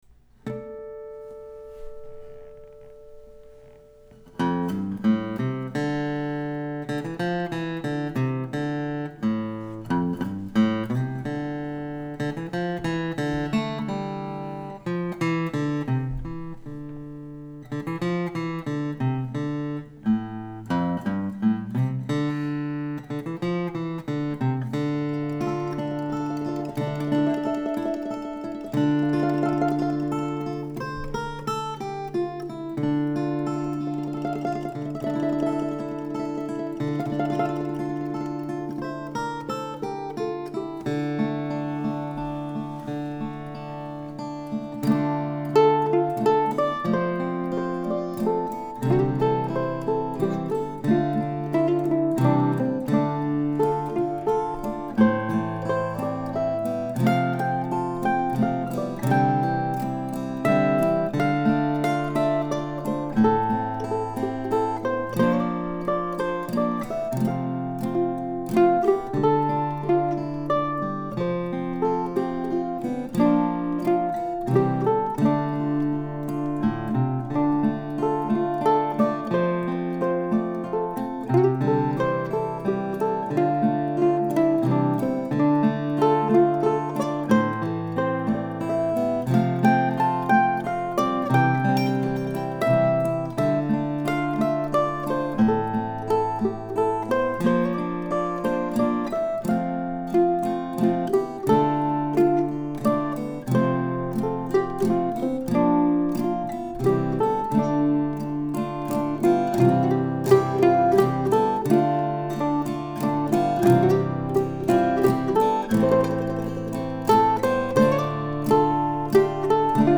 I guess the melody might seem a little somber for a bunny tune but I don't think of it that way. To me it's more of a warm and fuzzy feeling.
All of the guitar intro stuff just kind of happened as I was finally getting around to making a recording.
It's maybe not the guitar you want for playing hard and loud and the bass notes don't ring out like a bigger box but it's good for this.